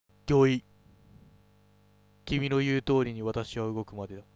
性別：男
口調：「男性的な口調。〜だ」
抑揚無く淡々と、ぶっきらぼうに喋るイメージ
↑キャラクターの音声（サンプルWMA形式）